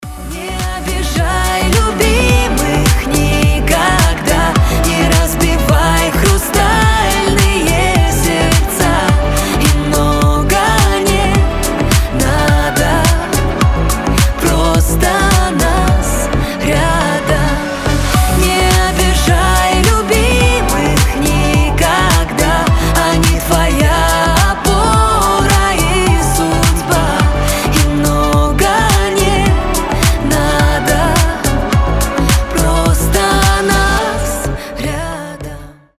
• Качество: 320, Stereo
красивые
дуэт